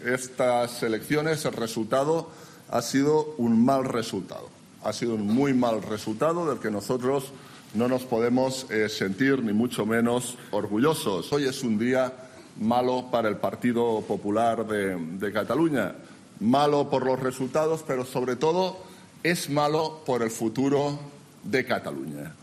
Albiol, que ha seguido el escrutinio electoral desde el hotel Grand Marina de Barcelona acompañado de diversos cargos de su partido, ha comparecido ante los medios para felicitar a Ciudadanos por la victoria en estas elecciones, al conseguir 37 escaños y más de un millón de votos, y reconocer que el PPC ha tenido un "muy mal resultado" del que no puede sentirse "orgulloso", sobre todo teniendo en cuenta que el bloque soberanista vuelve a sumar mayoría.